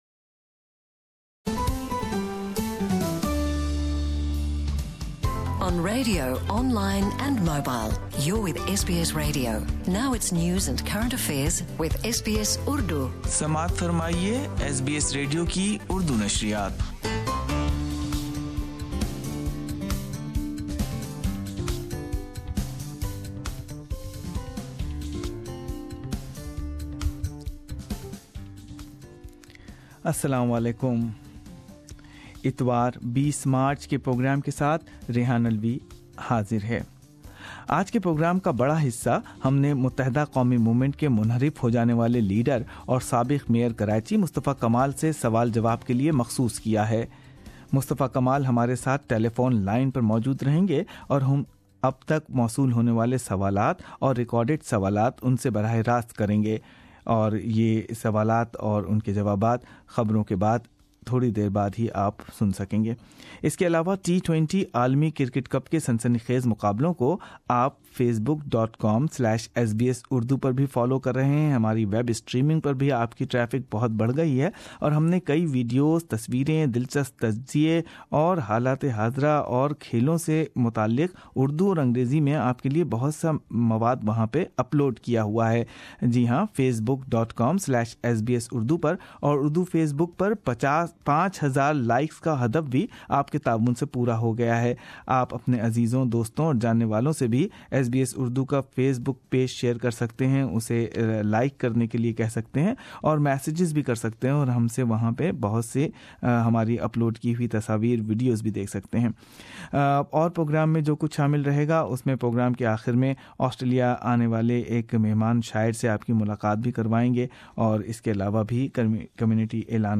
LIVE Response of Mustafa KAMAL in response of your bold Questions. ·Why you did not attempt to bring the change from within MQM instead of breaking it up?